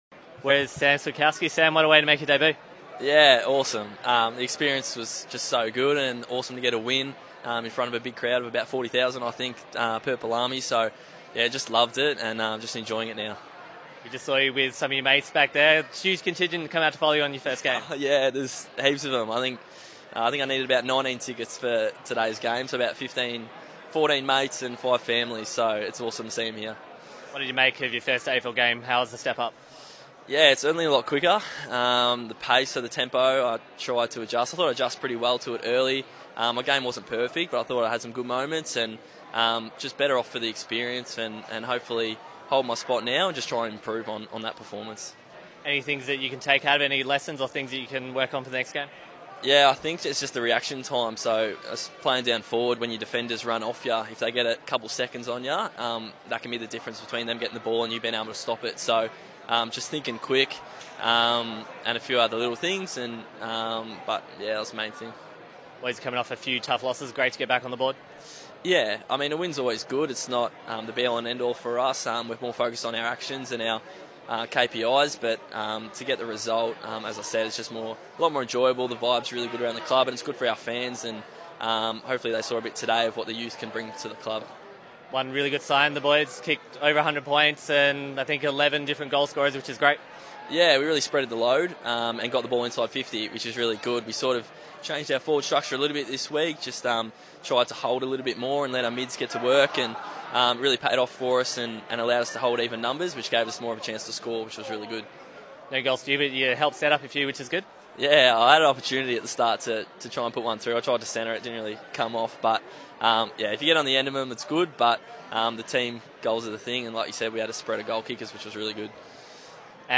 post-match: Rd 21 v Carlton